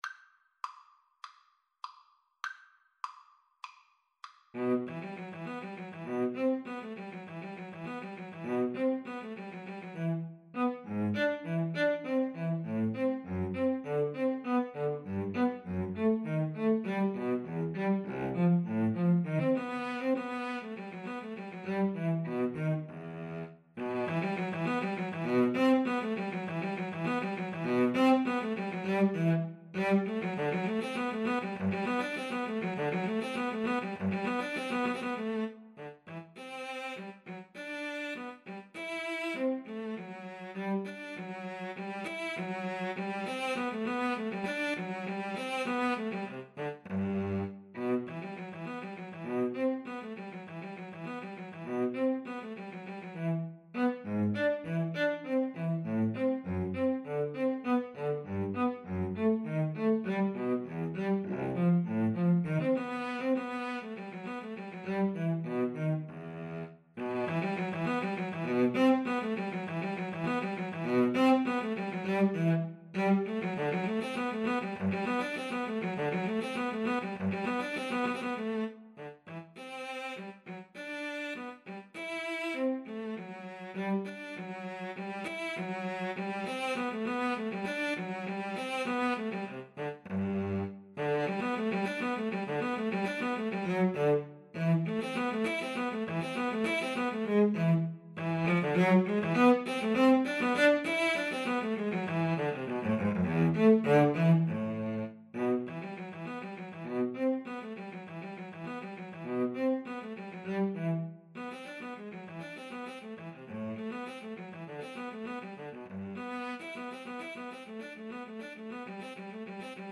~ = 100 Allegro (View more music marked Allegro)
Classical (View more Classical Violin-Cello Duet Music)